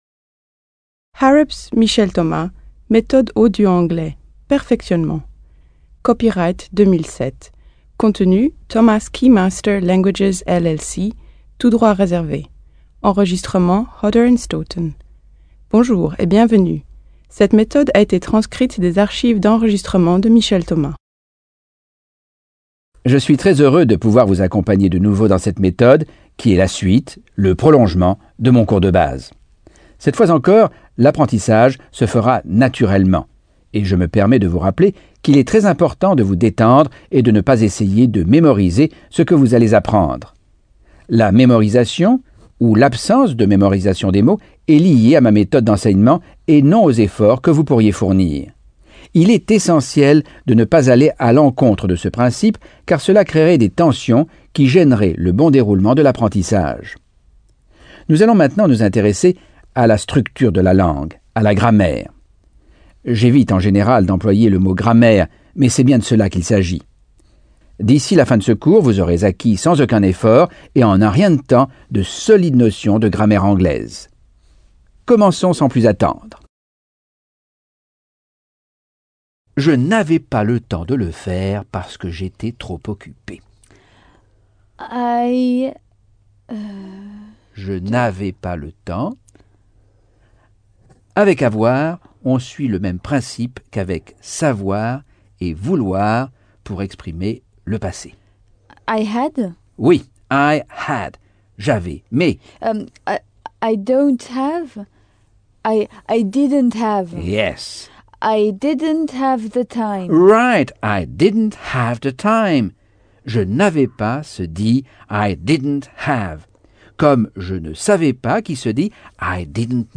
Leçon 1 - Cours audio Anglais par Michel Thomas - Chapitre 8